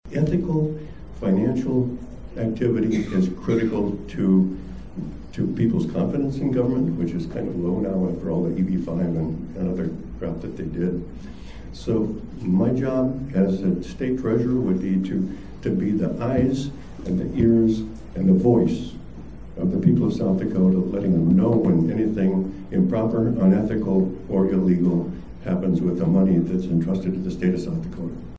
At their 2022 State Convention over the weekend (July 8-9, 2022) in Fort Pierre, the South Dakota Democratic Party nominated candidates for constitutional offices, adopted its platform, adopted an amendment to the constitution and passed resolutions.